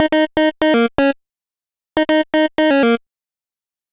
Blipp Riff_122_Eb.wav